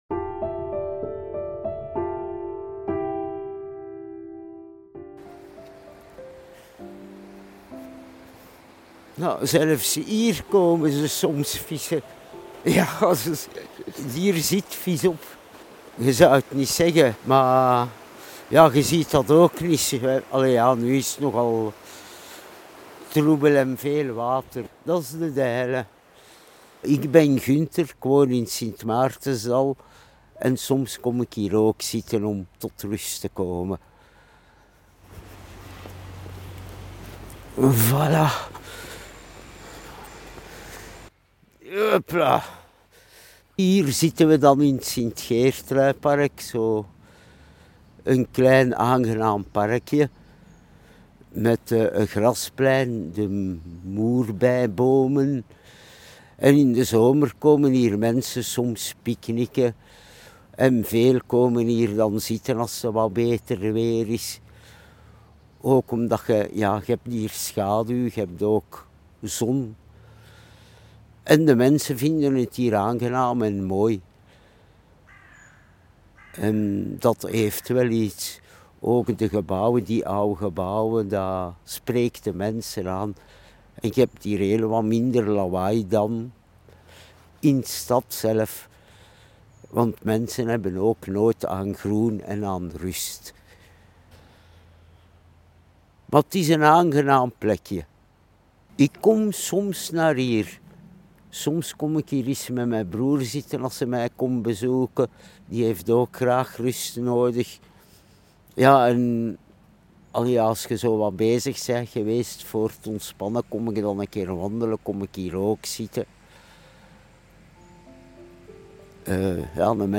Elk van hen nam ons ook mee naar een plek in de buurt waar zij zich goed voelen. Daar maakten we telkens een audioverhaal.